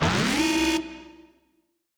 Sfx_creature_chelicerate_exoattack_alarm_01.ogg